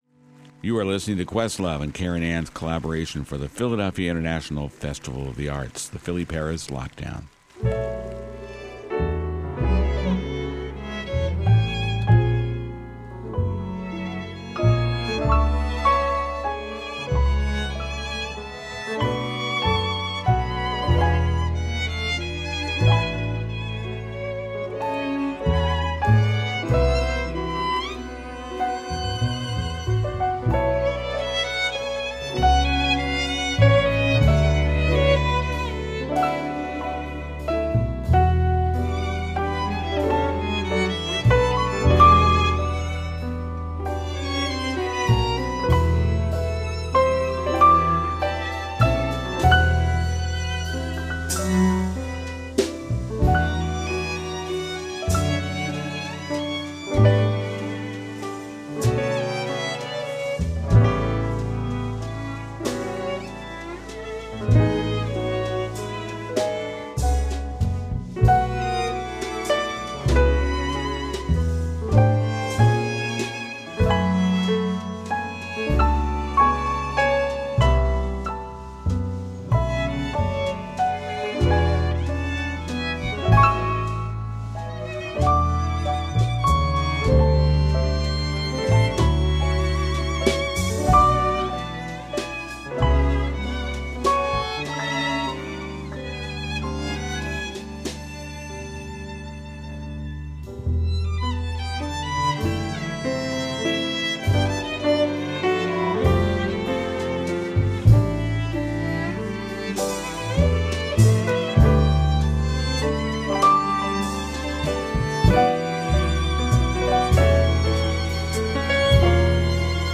jazz, classical and hip-hop styles